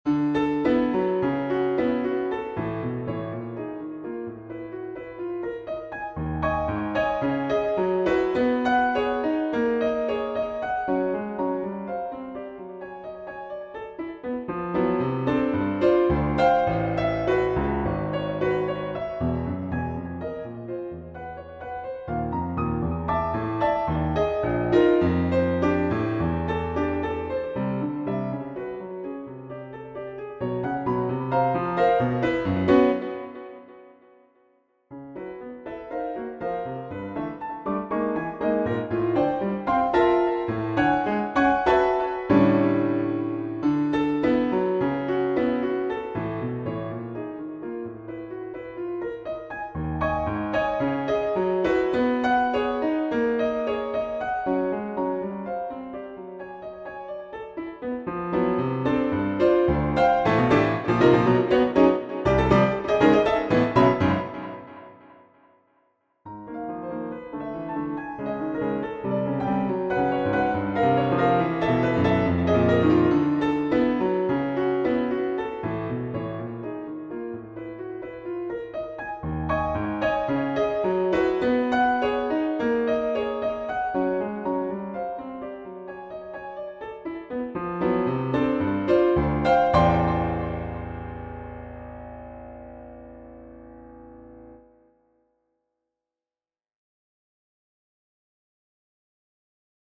Persichetti Exercise 4 - 37 for Piano
Another Persichetti prompted piano piece!